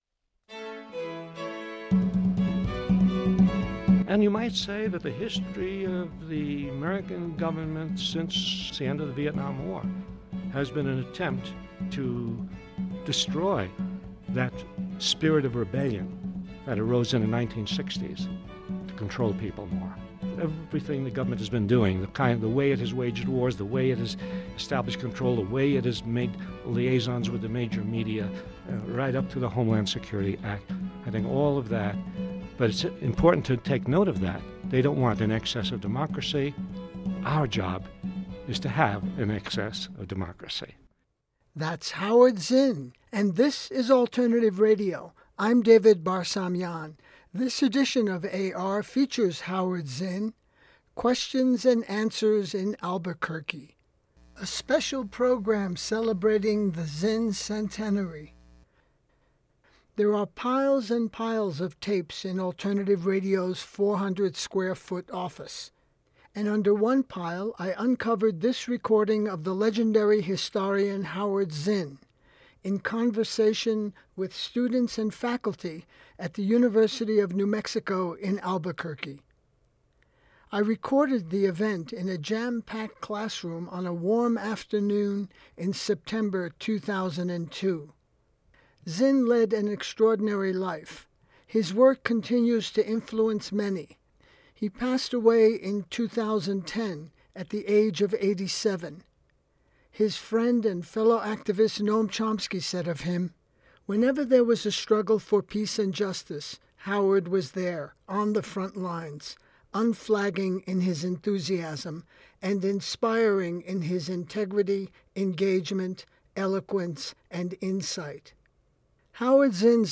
Howard Zinn – Questions & Answers in Albuquerque